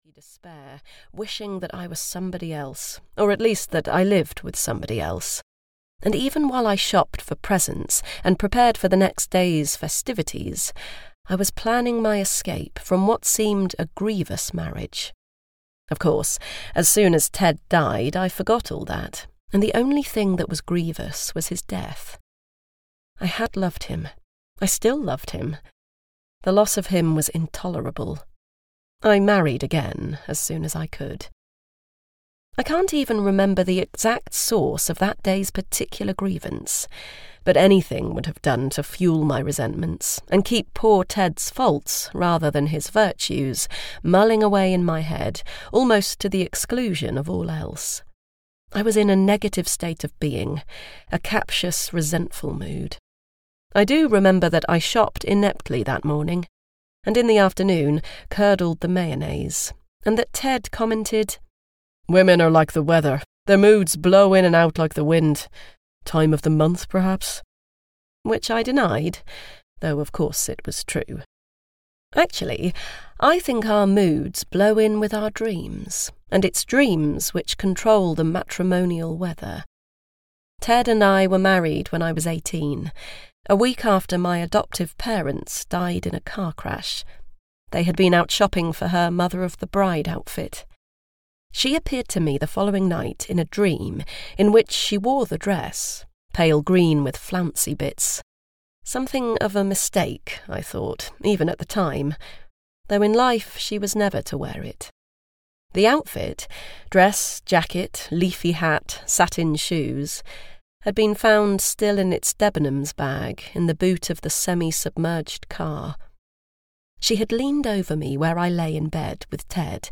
The Ted Dreams (EN) audiokniha
Ukázka z knihy